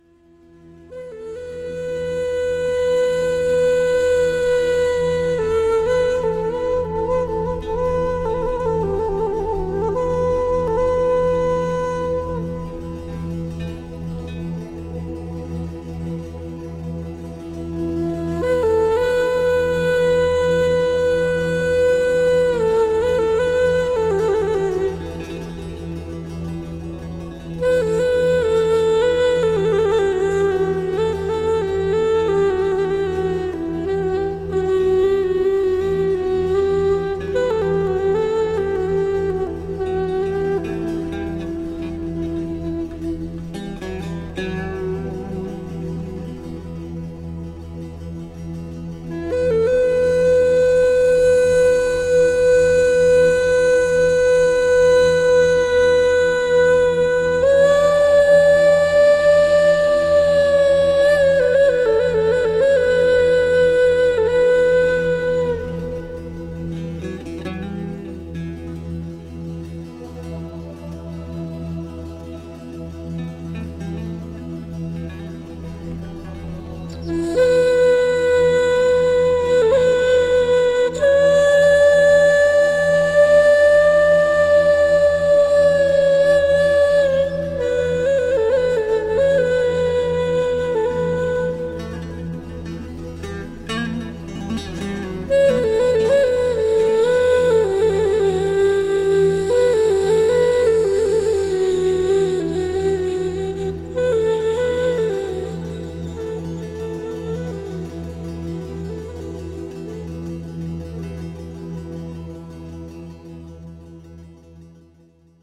classical Sufi group